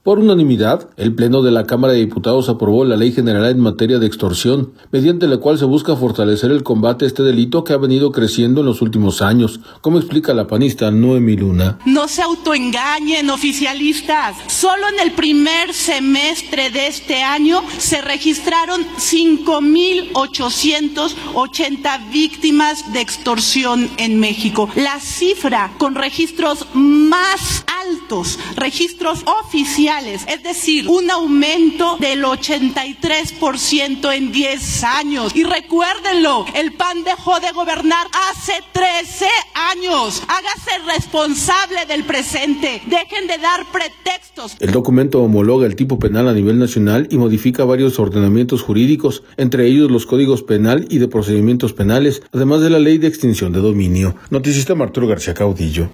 Por unanimidad, el Pleno de la Cámara de Diputados aprobó la Ley General en materia de Extorsión, mediante la cual se busca fortalecer el combate a este delito que ha venido creciendo en los últimos años, como explica la panista Noemí Luna.